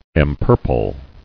[em·pur·ple]